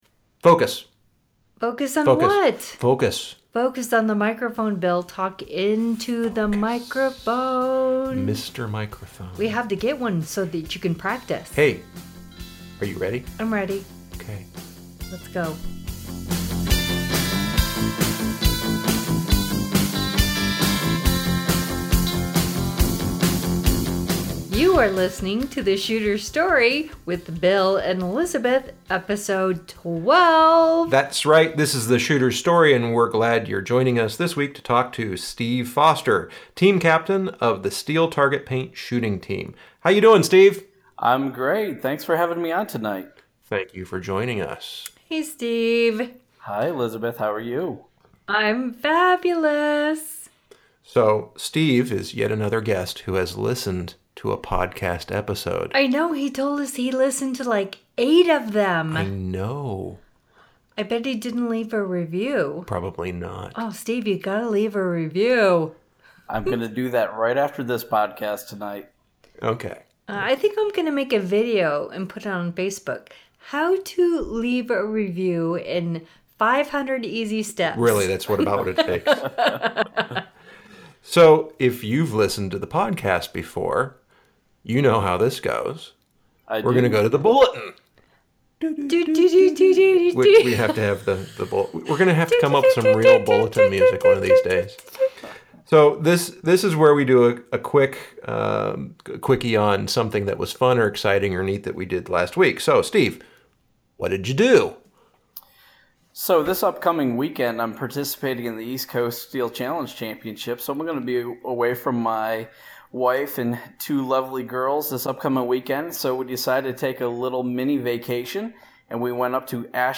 Interview – The Shooter’s Story